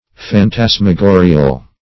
Search Result for " phantasmagorial" : The Collaborative International Dictionary of English v.0.48: Phantasmagorial \Phan*tas`ma*go"ri*al\, a. Of, relating to, or resembling phantasmagoria; phantasmagoric.